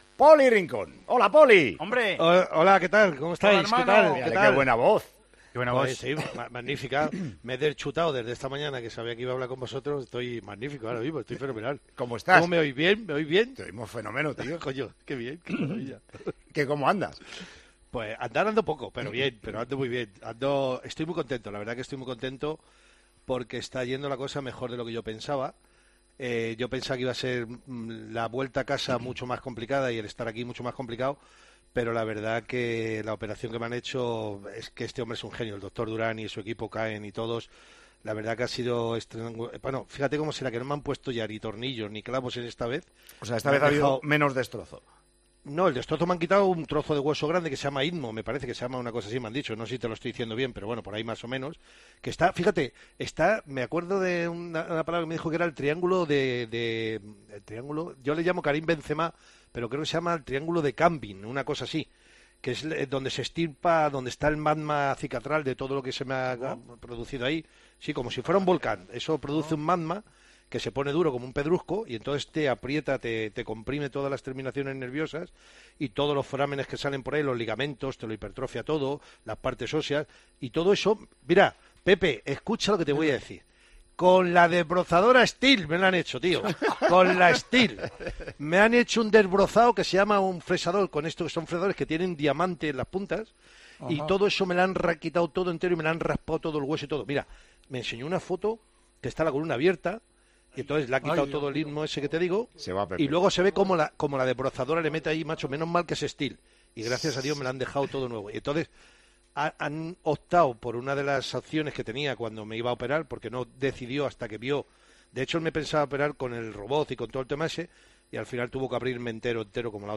Hablamos con nuestro querido Poli tras su operación de esta semana: "Pensaba que todo iba a ser más complicado. Esta vez no me han puesto ni tornillos ni clavos", nos cuenta.